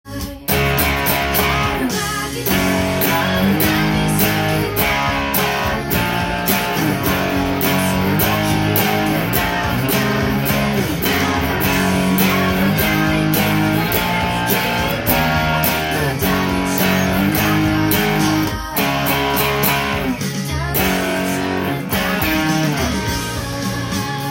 音源にあわせて譜面通り弾いてみました
殆どパワーコードで弾けるので少ない練習量で
（keyがC♯のため）